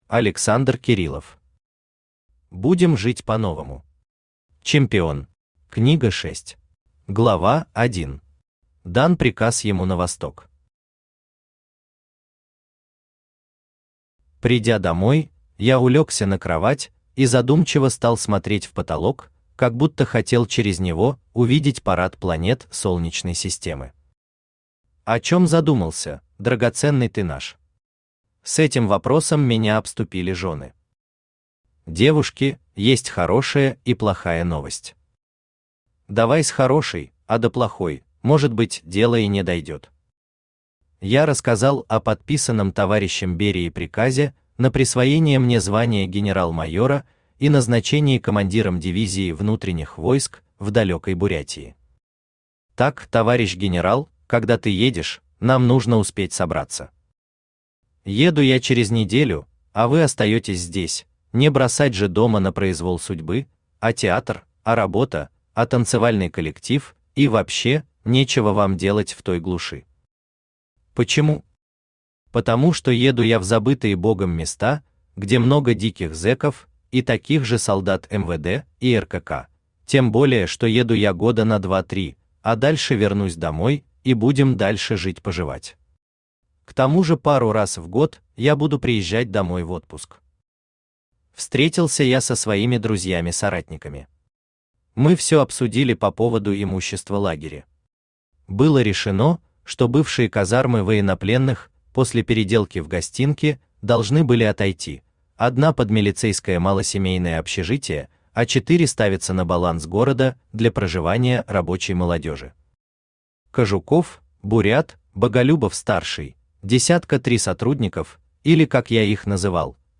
Книга 6 Автор Александр Леонидович Кириллов Читает аудиокнигу Авточтец ЛитРес.